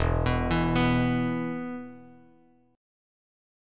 E5 Chord
Listen to E5 strummed